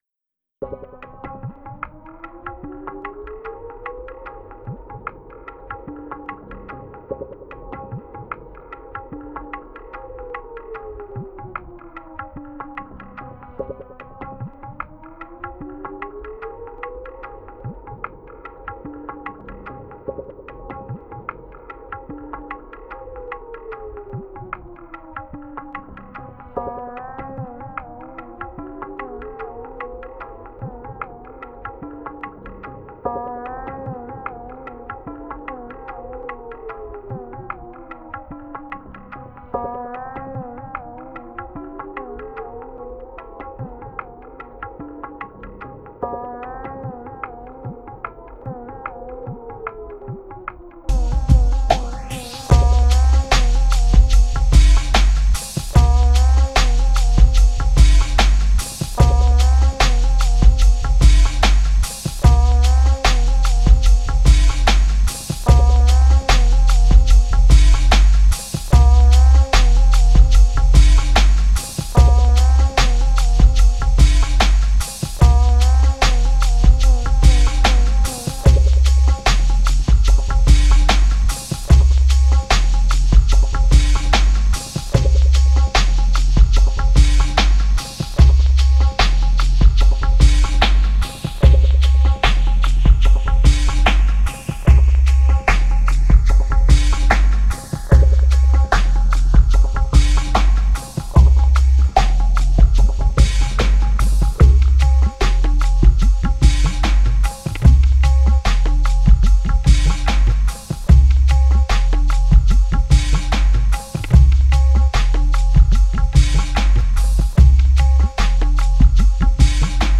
Genre: Dub, Downtempo, Tribal.